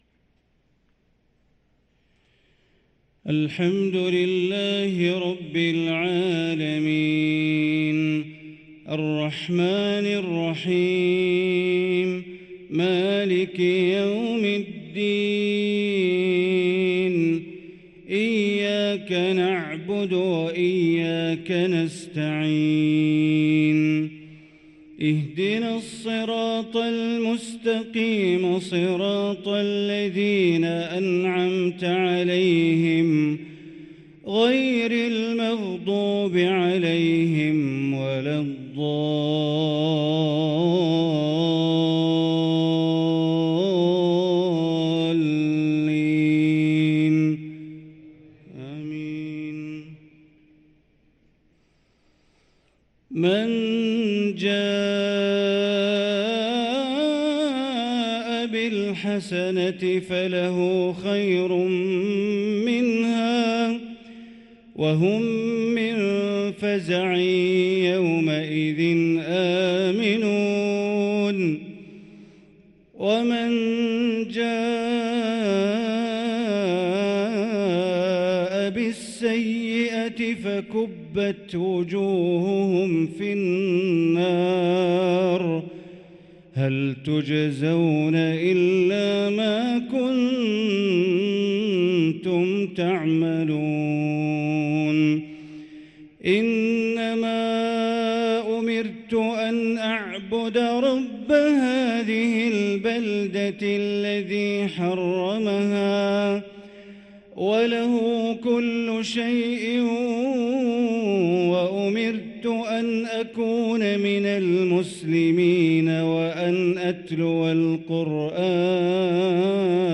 صلاة العشاء للقارئ بندر بليلة 5 شوال 1444 هـ